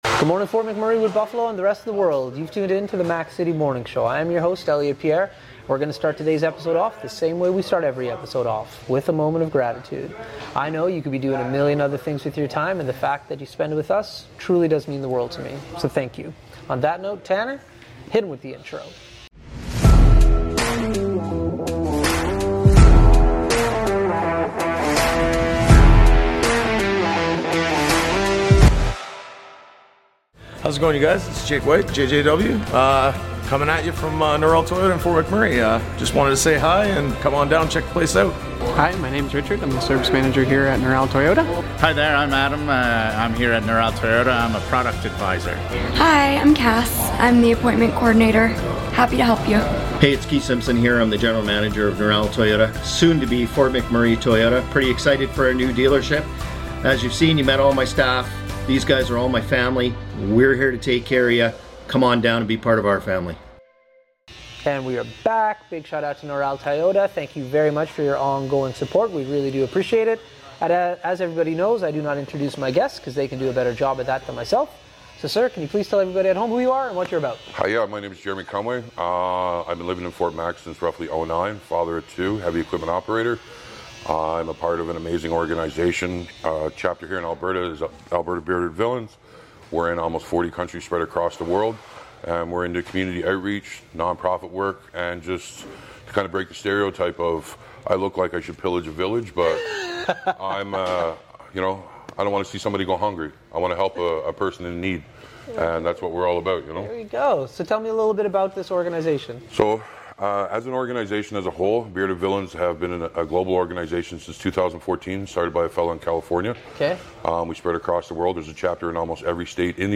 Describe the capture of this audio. We are on location at Thickwood Barbershop today